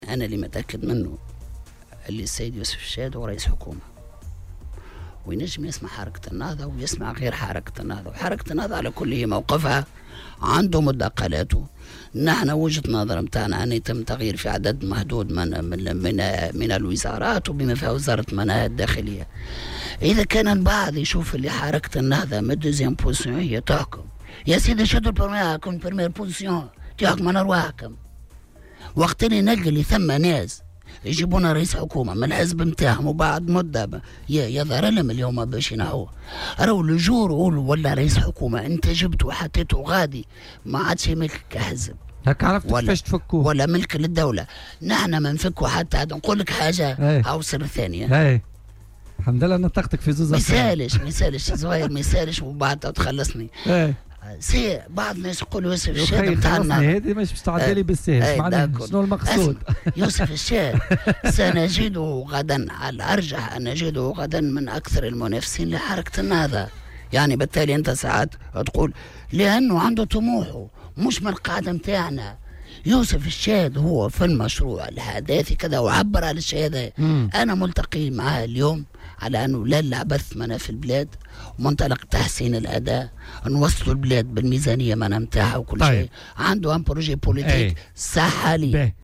وأضاف الجلاصي خلال استضافته في "بوليتيكا" اليوم الخميس 9 أوت 2018، أن الشاهد له طموحه ومشروعه السياسي، وسيكون غدا من أشرس المنافسين للنهضة، لكنها تلتقي معه اليوم في مبدأ عدم العبث في البلاد وإنقاذها وتحسين الأداء".وجدّد الجلاصي تمسك الحركة بعدم تغيير الحكومة بأكملها، مقابل إجراء تحوير جزئي يشمل بعض الوزارات.